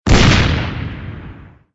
MG_cannon_fire_alt.ogg